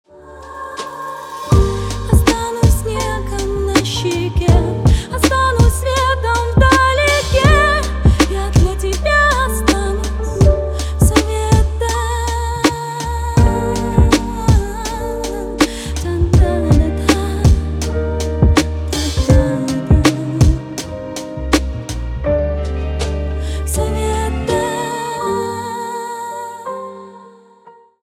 хип-хоп
русский рэп